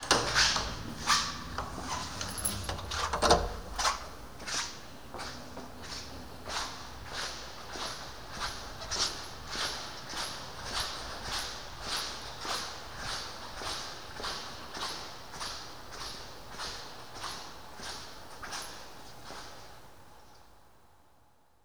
Index of /90_sSampleCDs/Propeller Island - Cathedral Organ/Partition N/DOORS+STEPS
CH.-DOOR 2-L.wav